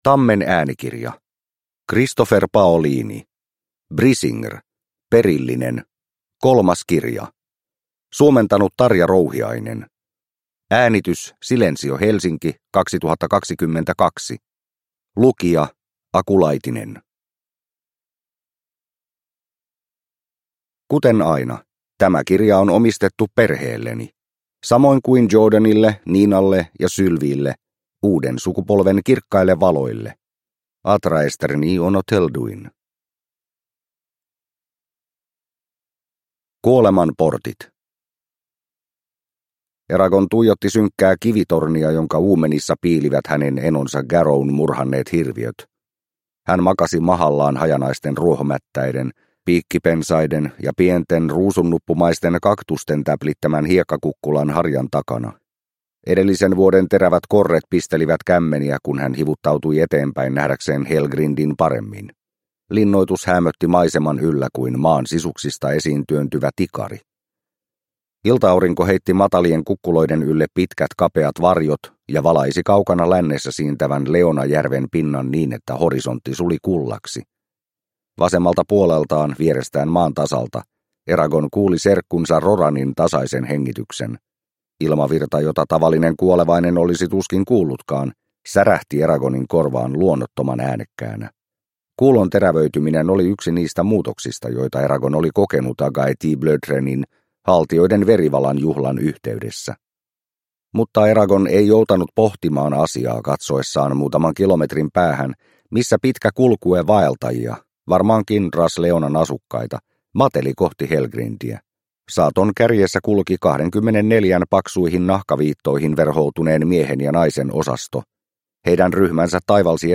Brisingr – Ljudbok – Laddas ner